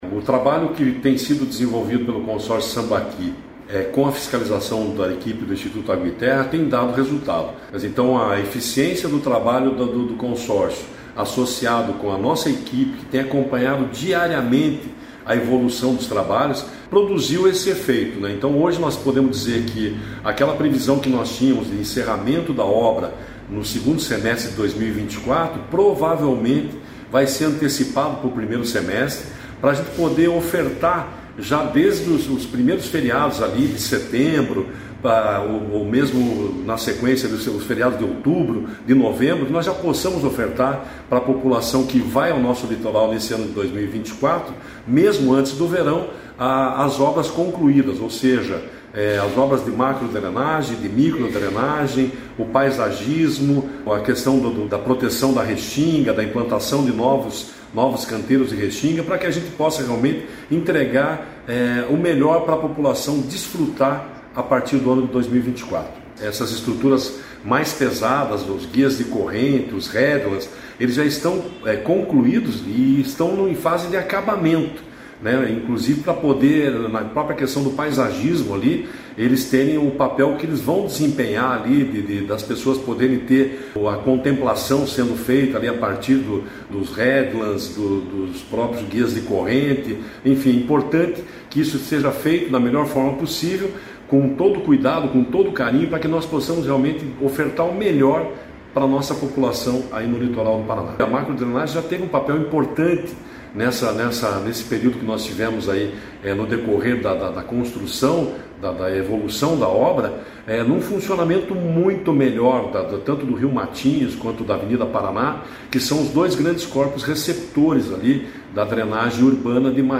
Sonora do diretor-presidente do IAT, Everton Souza, sobre o mais recente boletim da revitalização da Orla de Matinhos, que alcançou 92%